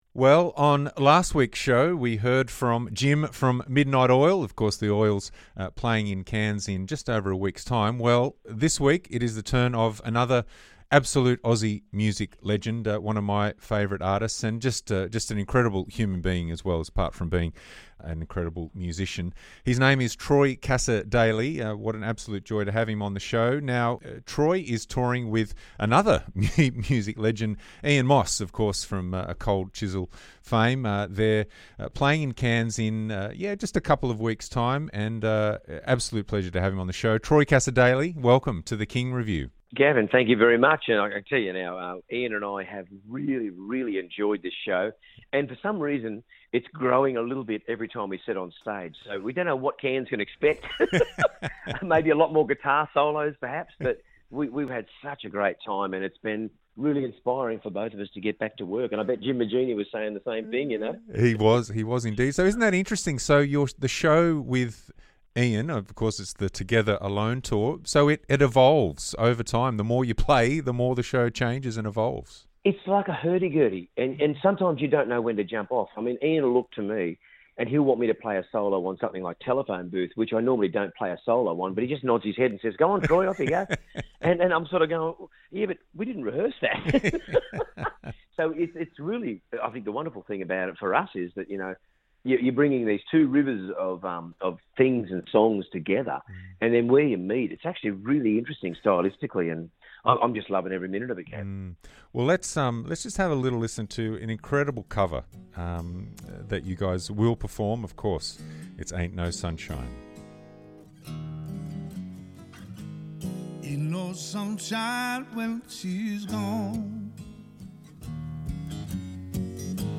The King Review - Gavin chats with country music legend Troy Cassar-Daley about his upcoming gig in Cairns plus the power of music to heal grief after the death of his father - 1 April 2022